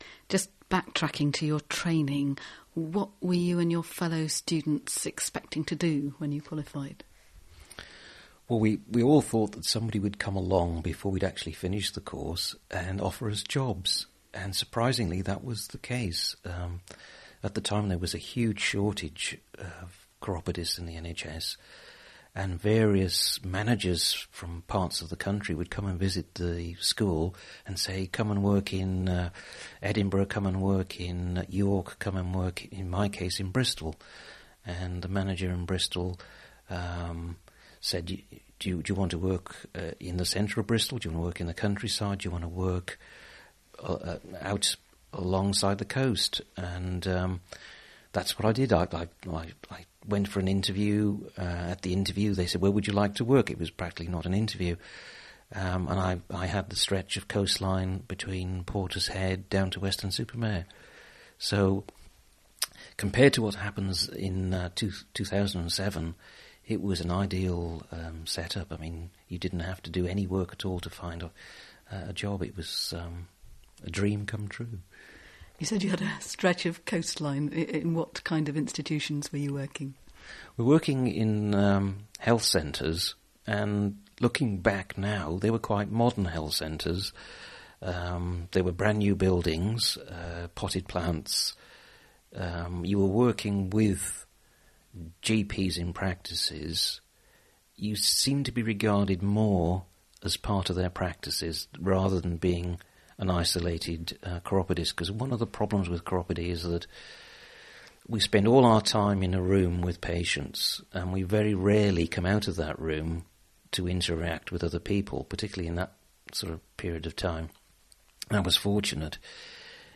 Available interview tracks